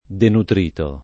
denutrito [ denutr & to ] agg.